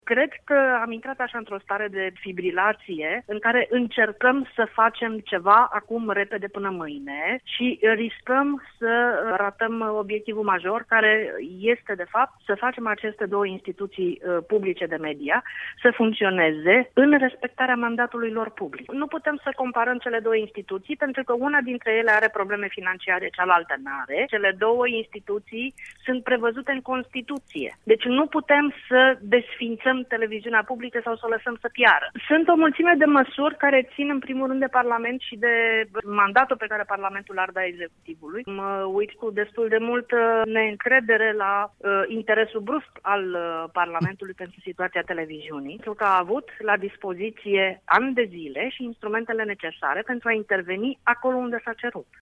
invitată la Bucuresti fm a precizat ca modificarea legii 41 nu rezolvă problema financiară a Televiziunii publice.